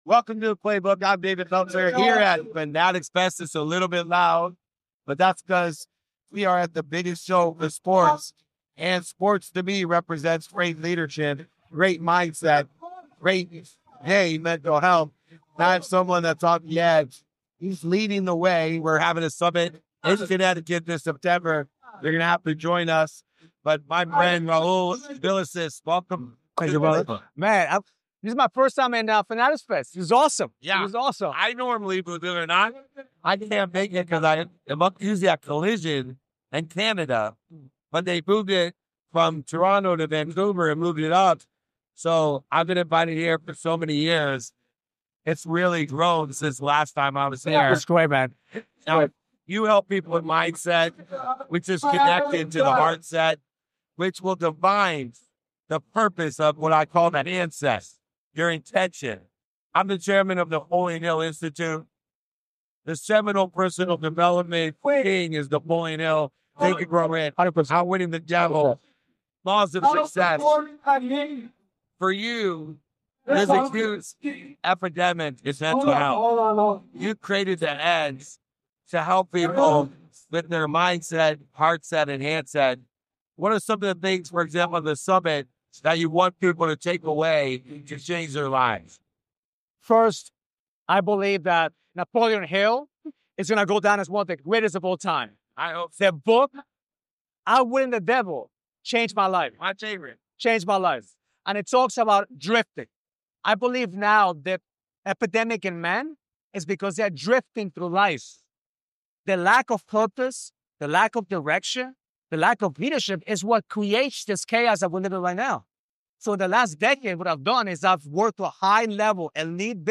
This conversation explores what it takes to reinvent yourself, lead with purpose, and build a life aligned with your highest potential.